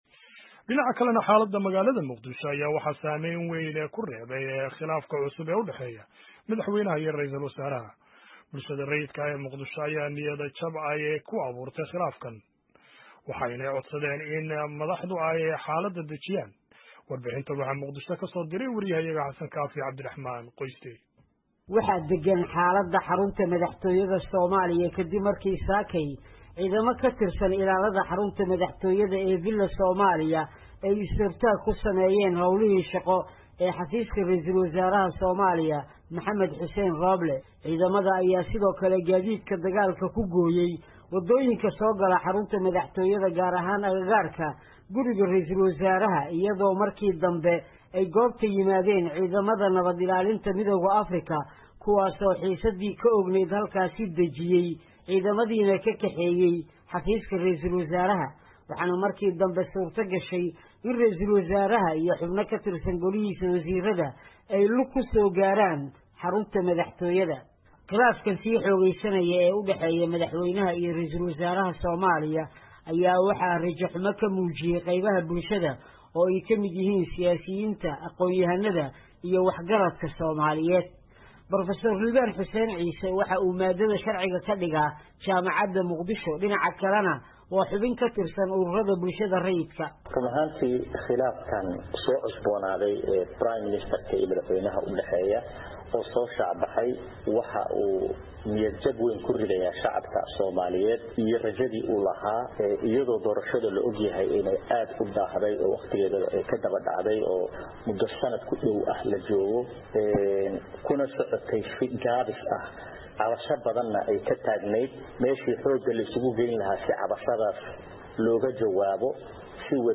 Warbixntan